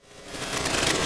TM88 JokeFX.wav